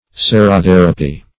Search Result for " serotherapy" : The Collaborative International Dictionary of English v.0.48: Serotherapy \Se`ro*ther"a*py\, n. (Med.)